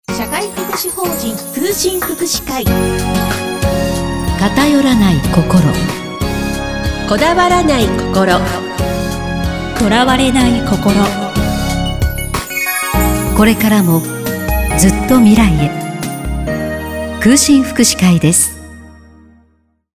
11月より、76.3FM エフエムいかる様で、朝昼夕1日3回 CM を流します。
エフエムいかるCM-20sec.mp3